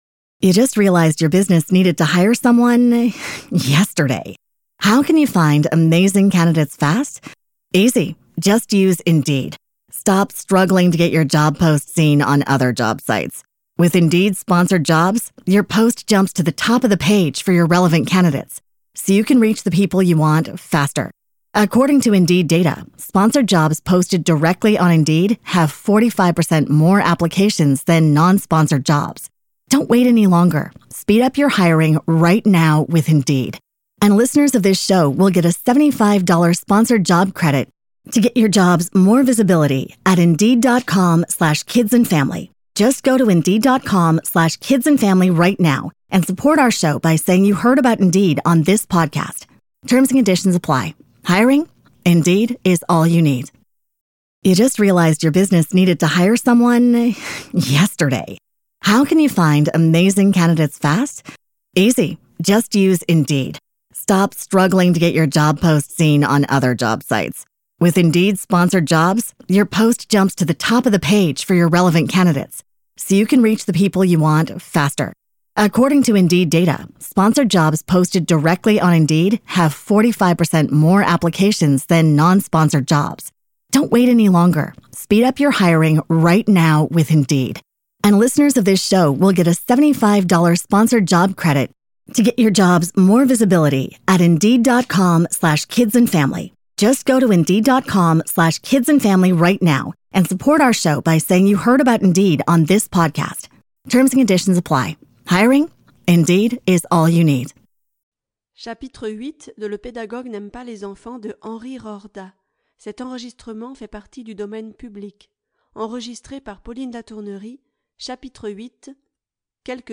Livres Audio